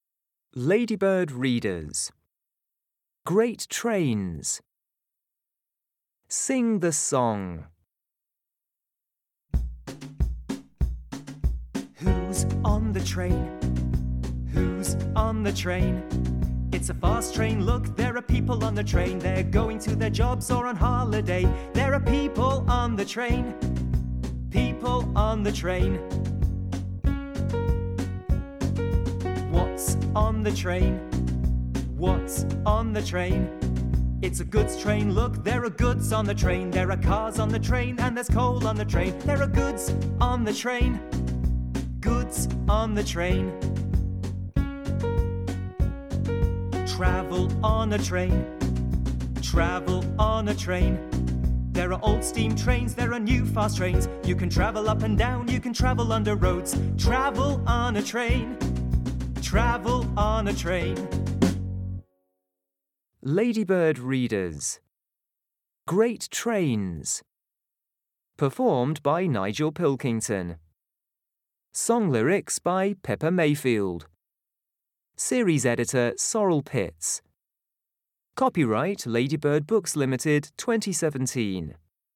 Song -